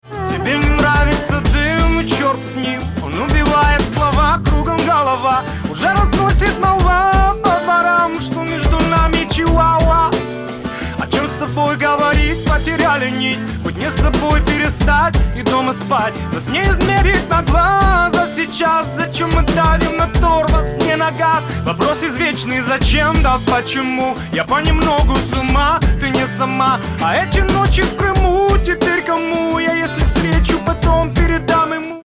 • Качество: 128, Stereo
гитара
мужской вокал
попса